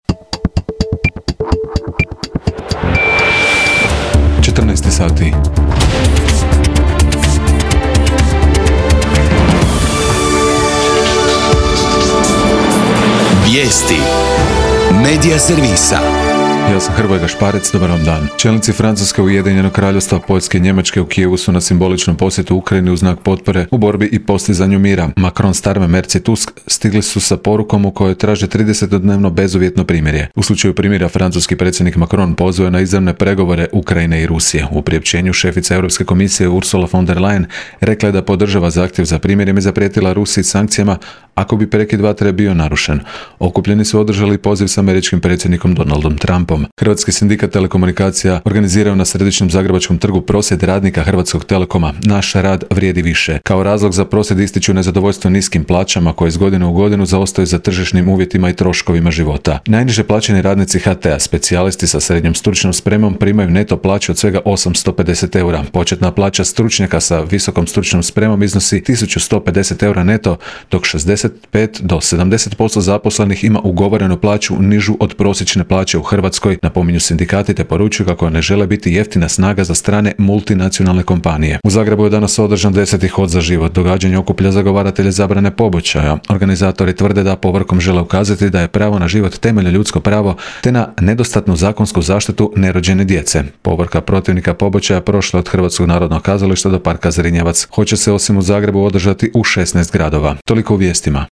VIJESTI U 14